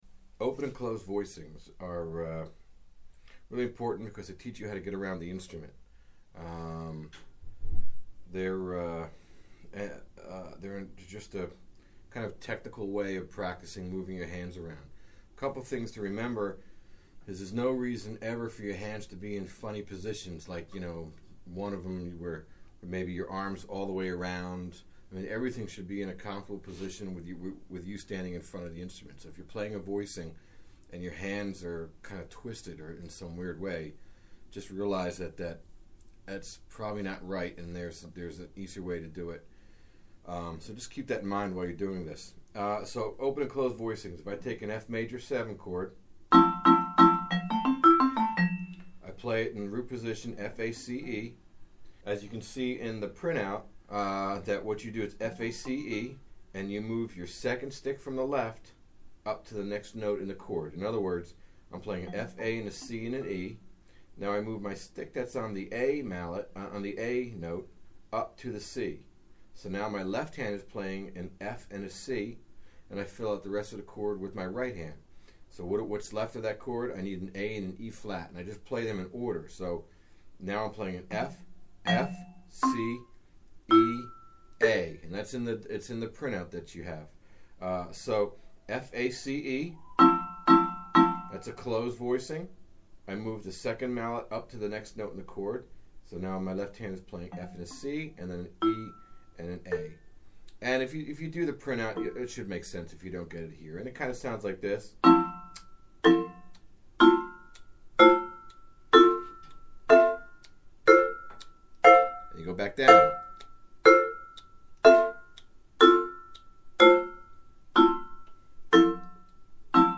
原位F,A,C,E. 然後只把A這個音移到高八度，得到F,C,E,A和弦。
速度要慢慢開始，重點是節拍器開起來，照著節奏打(play in time)。